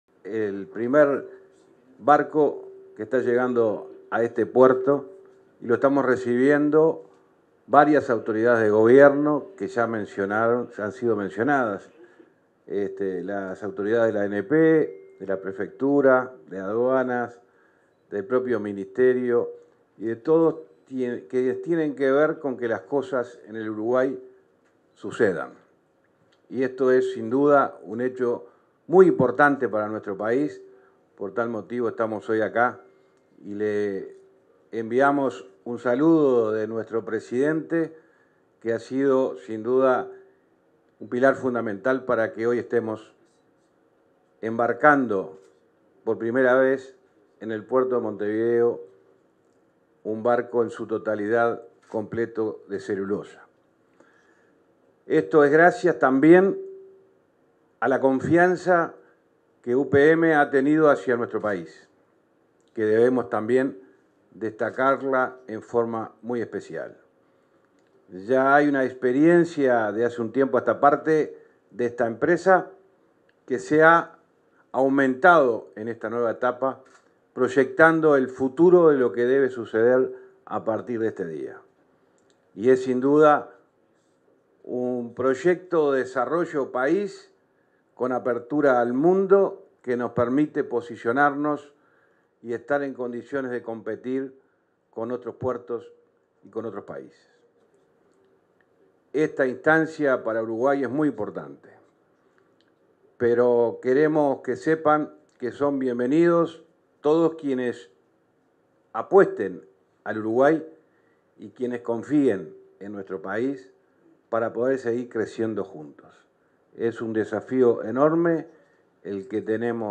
Palabras del ministro de Transporte y Obras Públicas, José Luis Falero
Palabras del ministro de Transporte y Obras Públicas, José Luis Falero 23/05/2023 Compartir Facebook Twitter Copiar enlace WhatsApp LinkedIn UPM 2 realizó su primer despacho de unas 20.000 toneladas de celulosa a través de la terminal especial que la empresa finlandesa construyó en el puerto de Montevideo. En la oportunidad, se expresó el ministro de Transporte y Obras Públicas, José Luis Falero.